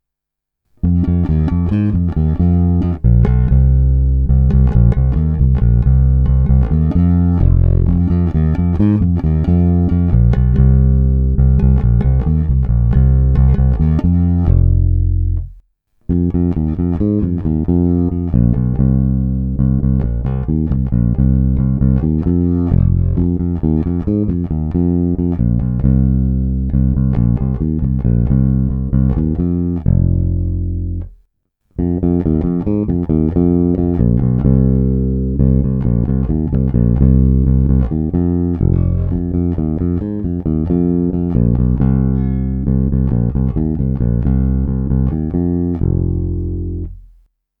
Zvonivý, vrnivý, pevný.
Není-li uvedeno jinak, následující nahrávky jsou provedeny rovnou do zvukovky a kromě normalizace ponechány bez dodatečných úprav.